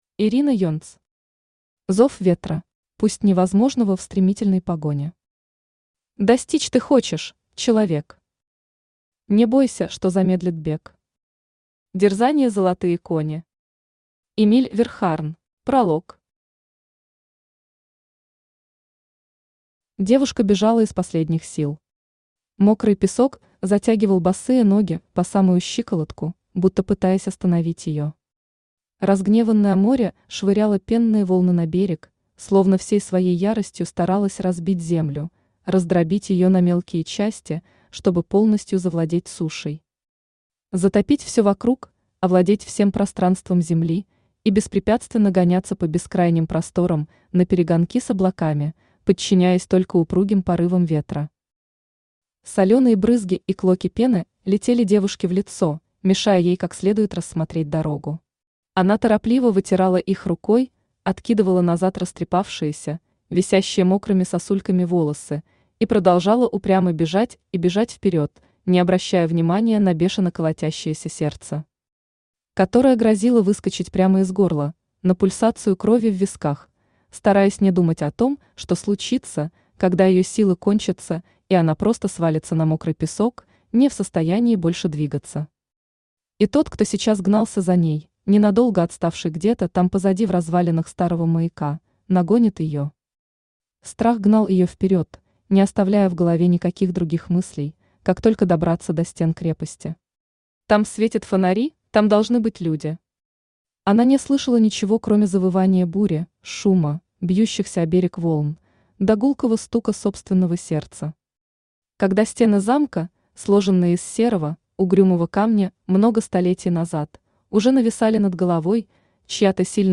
Аудиокнига Зов ветра | Библиотека аудиокниг
Aудиокнига Зов ветра Автор Ирина Юльевна Енц Читает аудиокнигу Авточтец ЛитРес.